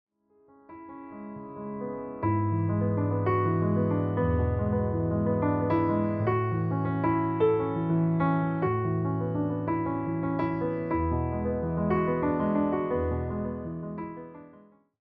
With its steady, expressive piano style